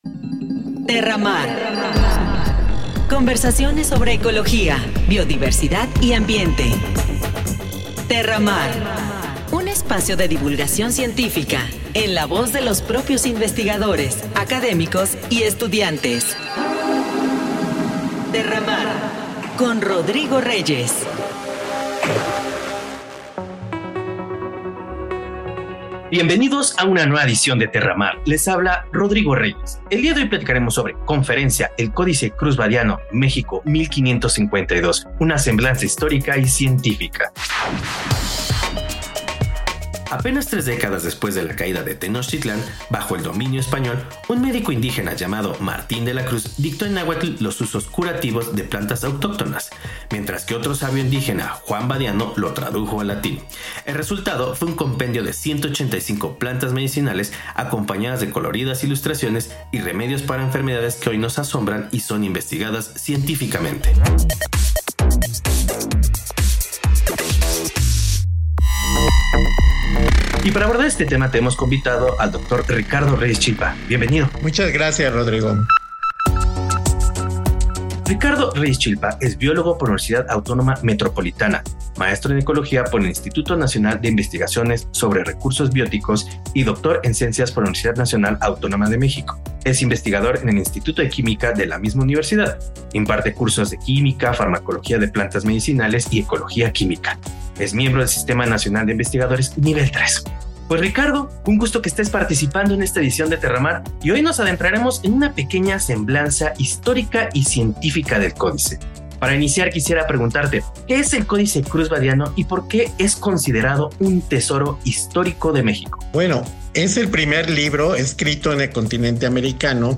CONFERENCIA, EL CODICE CRUZ BADIANO, MEXICO, 1552. UNA SEMBLANZA HISTORICA Y CIENTIFICA – RadioCiencia